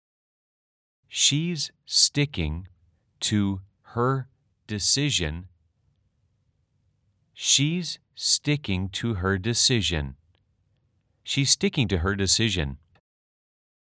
/ 쉬 스띠킹 투허 / 디씨이전 /
원어민 음성파일을 따로 저장하지 않아도 스마트 폰으로 바로 들을 수 있습니다.